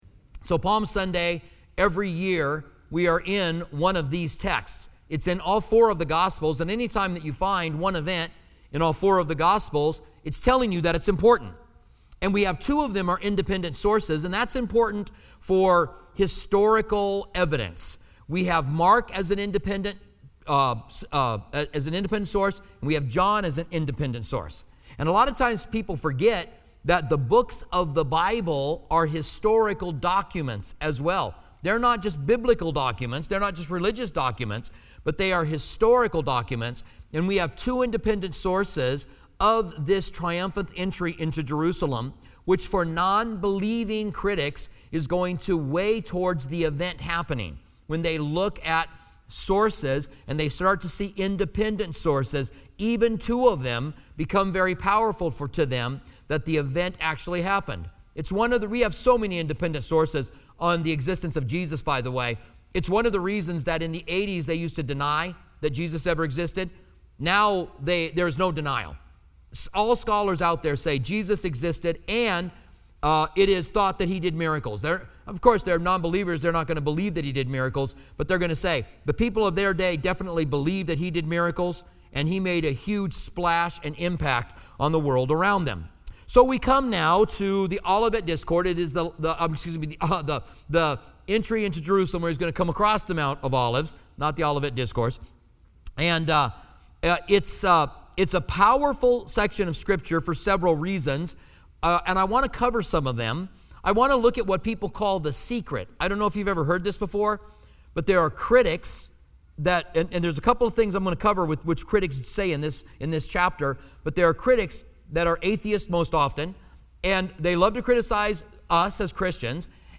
Palm Sunday message